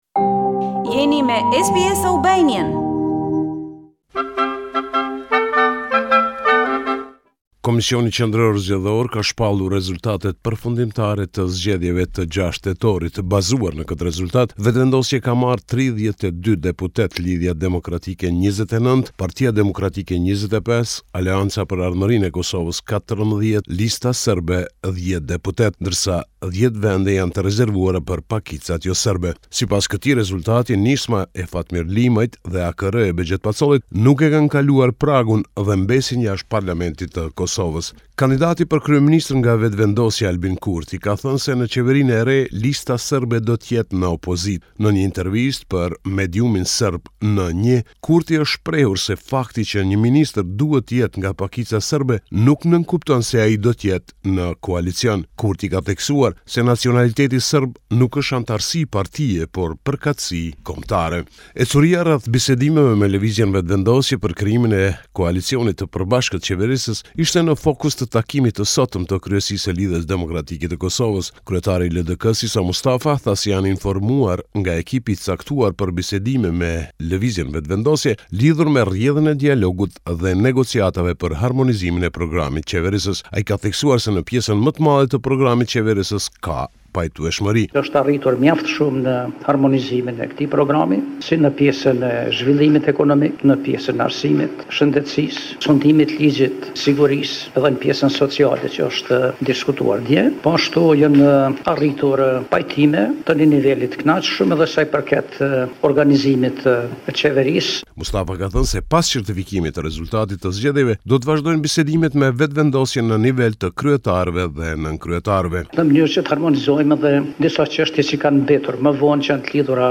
This is a report summarising the latest developments in news and current affairs in Kosovo.